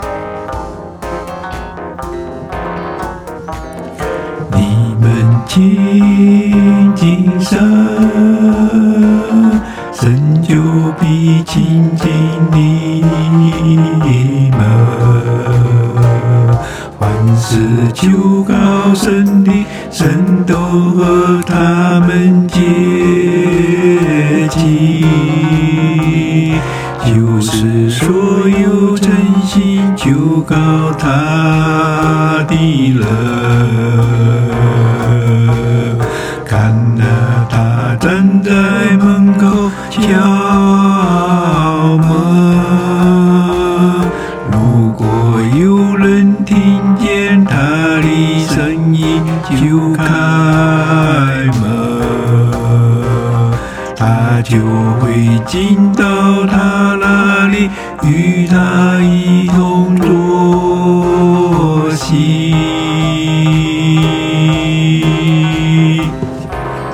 诗歌 . permalink .